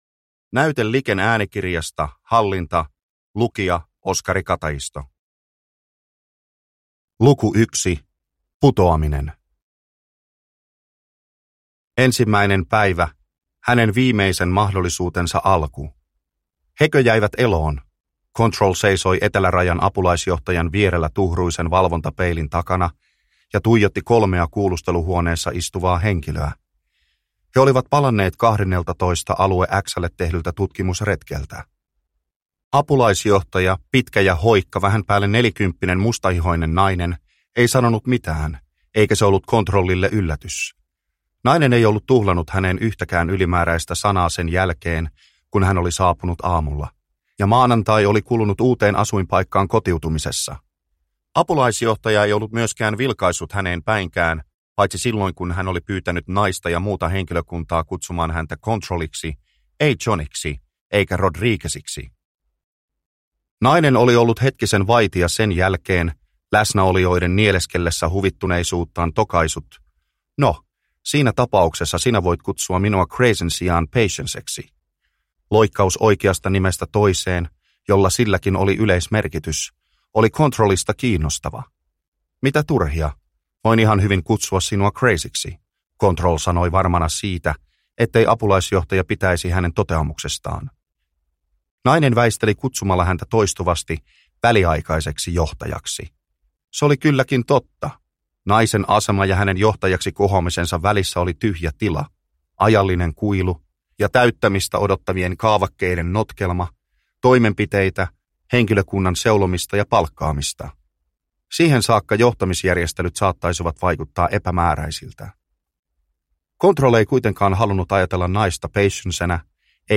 Hallinta – Ljudbok – Laddas ner